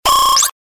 FX-276-BREAKER
FX-276-BREAKER.mp3